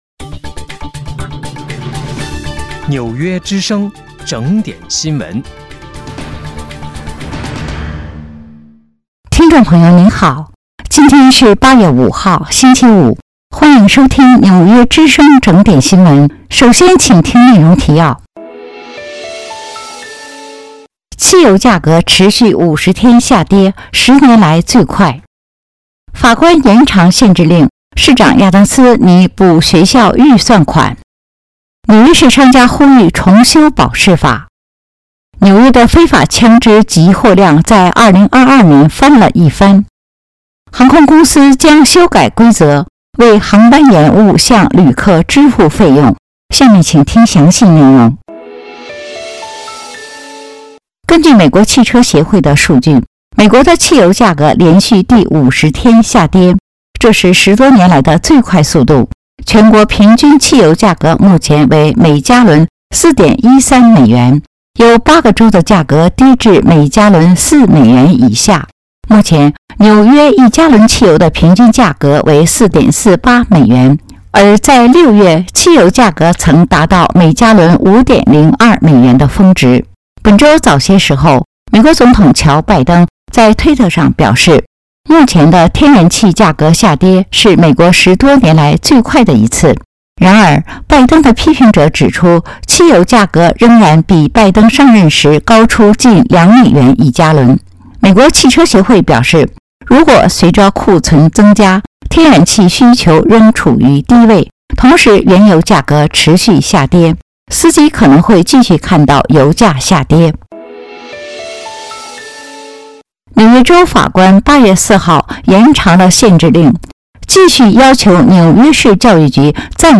8月5日（星期五）纽约整点新闻